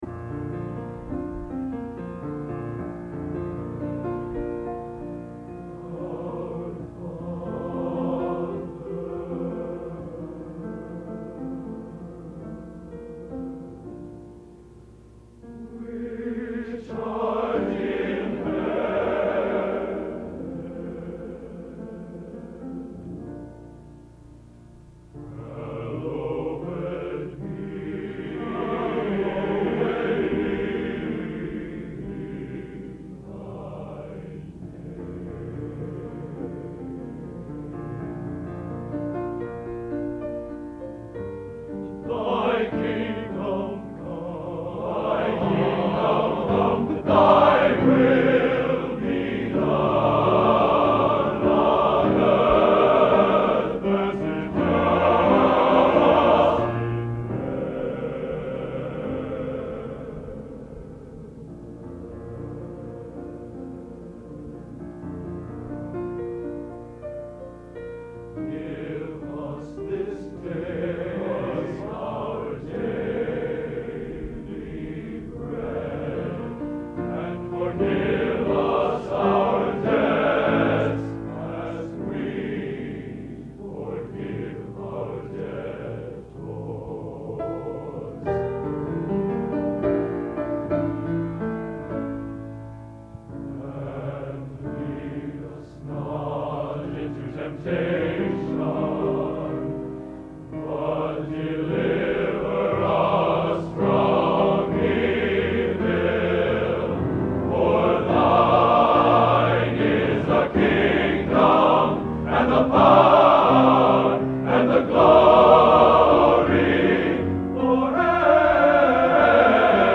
Location: West Lafayette, Indiana
Genre: Sacred | Type: End of Season